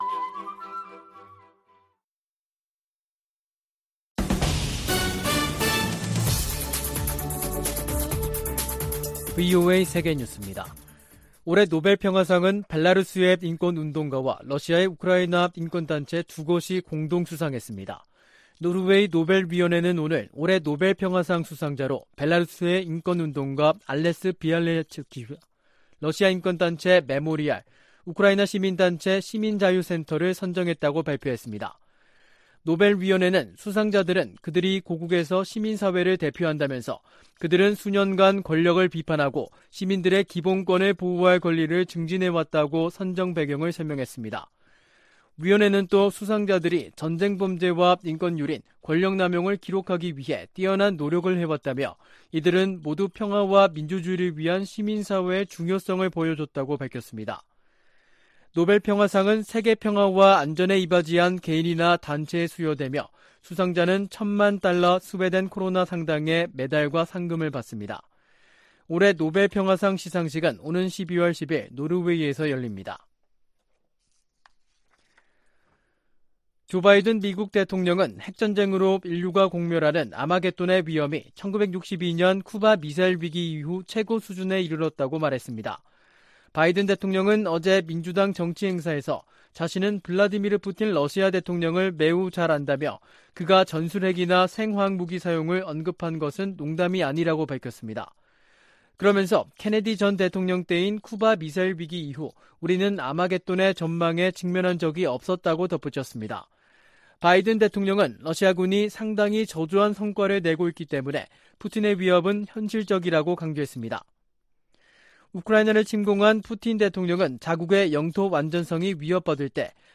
VOA 한국어 간판 뉴스 프로그램 '뉴스 투데이', 2022년 10월 7일 2부 방송입니다. 한국 정부는 북한이 7차 핵실험을 감행할 경우 9.19 남북 군사합의 파기를 검토할 수 있다고 밝혔습니다. 미국 정부는 북한의 탄도미사일 발사 등 도발에 대응해 제재를 포함한 여러 방안을 활용할 것이라고 밝혔습니다. 미 국방부는 북한의 최근 탄도미사일 발사에 대해 무책임하고 불안정한 행동을 즉시 중단할 것을 촉구했습니다.